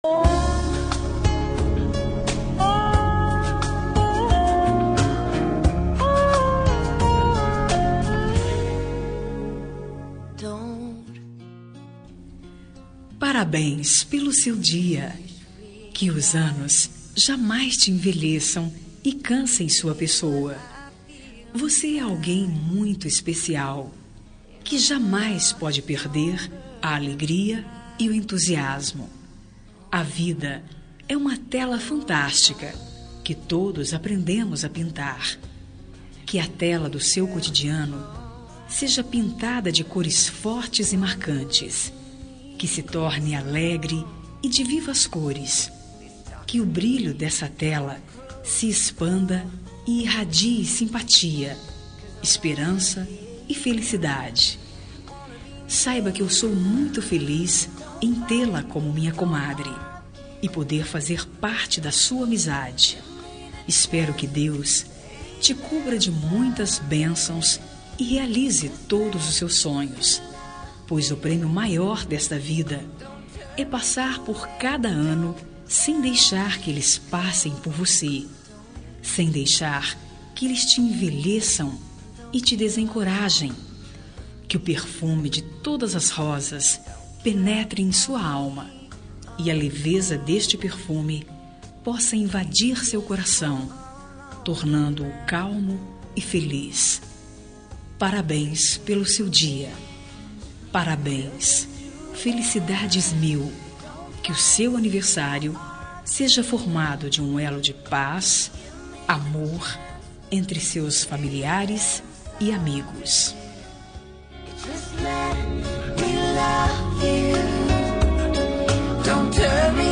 Aniversário de Comadre – Voz Feminina – Cód: 202160